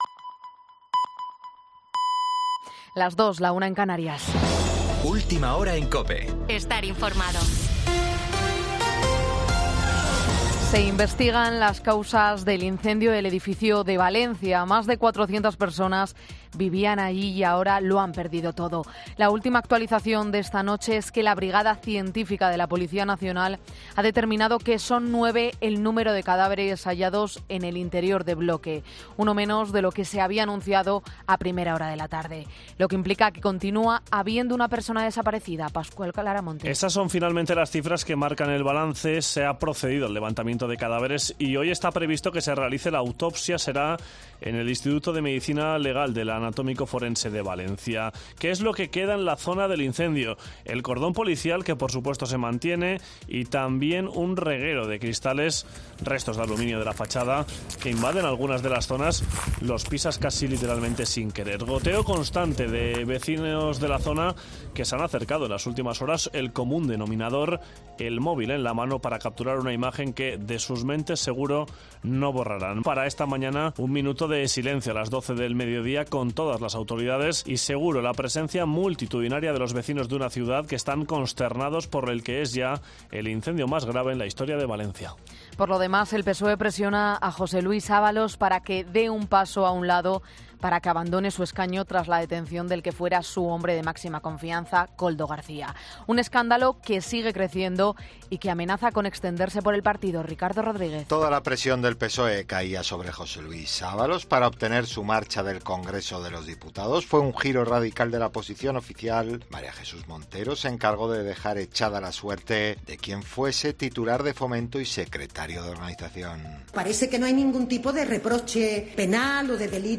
Boletín 02.00 horas del 24 de febrero de 2024